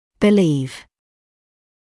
[bɪ’liːv][би’лиːв]полагать, считать, думать; верить